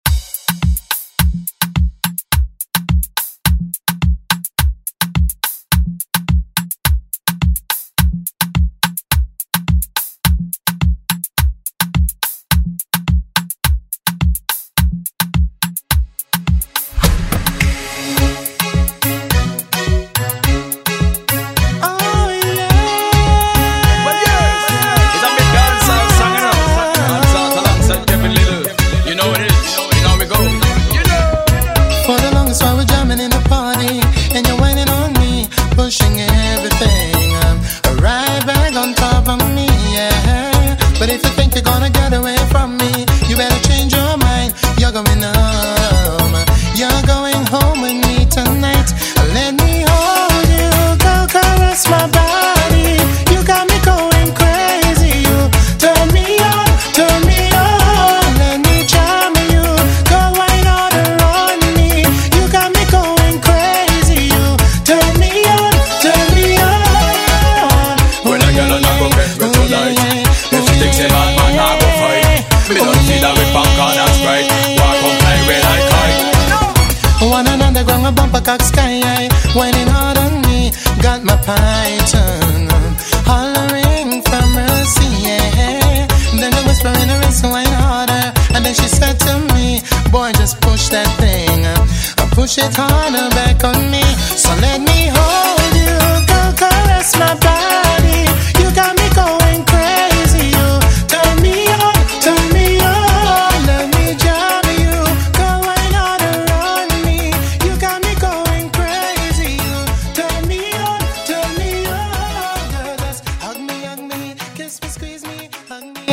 Genres: 70's , RE-DRUM